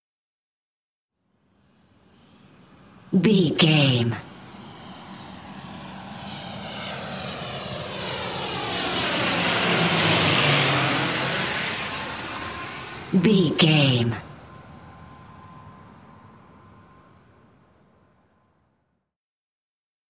Airplane passby
Sound Effects